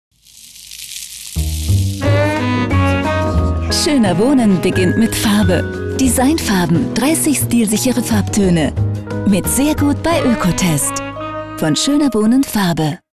Ausgebildete Sprecherin und Schauspielerin mit variabler warmer Stimme, bekannt aus Radio- und TV.
deutsche Sprecherin, German VoiceTalent.
Sprechprobe: Werbung (Muttersprache):
german female voice over talent.
Werbung - TV - Schöner Wohnen Ökotest.mp3